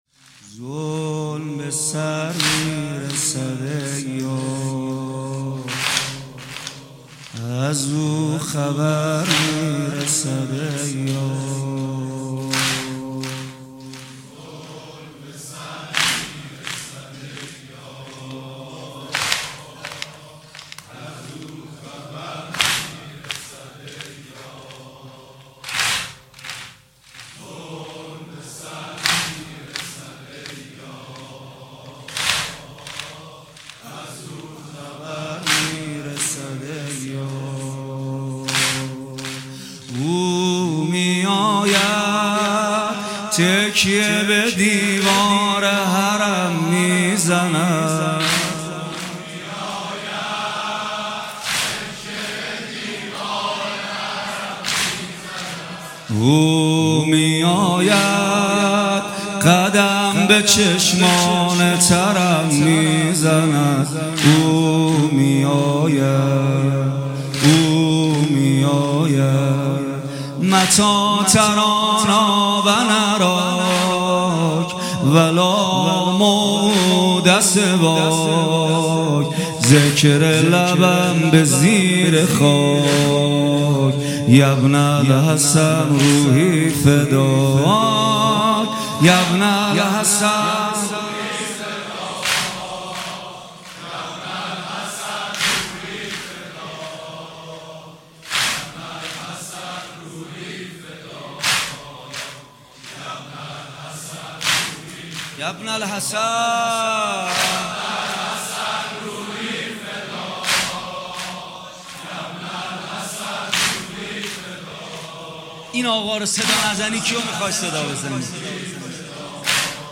شب دوم در امامزاده‌ صالح‌ تجریش
بخش ۱ : روضه